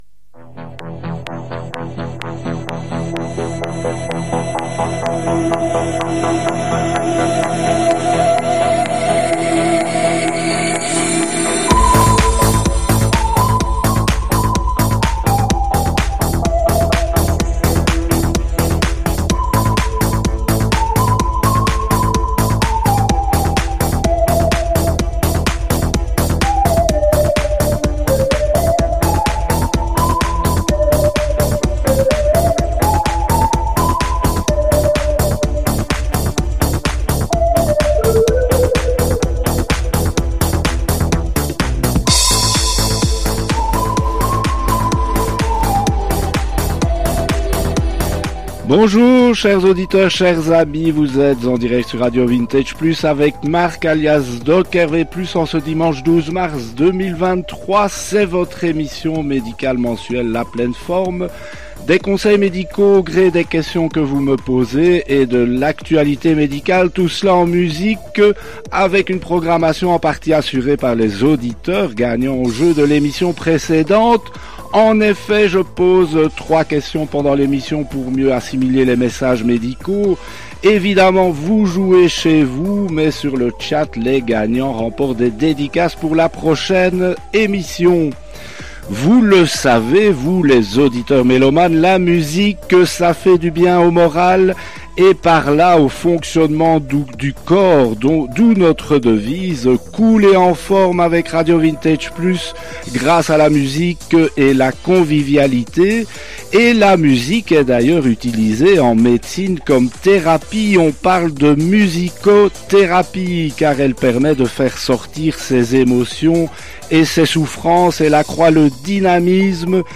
Cette émission médicale et musicale mensuelle nouvelle formule a été diffusée en direct le dimanche 12 mars 2023 à 10 heures depuis les studios belges de RADIO VINTAGE PLUS.
Sont abordés: la musicothérapie, les perturbateurs endocriniens et polluants éternels, le vaccin contre le papillomavirus, les précautions pour éviter les accidents sportifs ou autres, et le COVID long. La programmation musicale est en partie assurée par les dédicaces des gagnants du jeu de l’émission précédente, qui évoquent les souvenirs laissés par les chansons qu’ils proposent.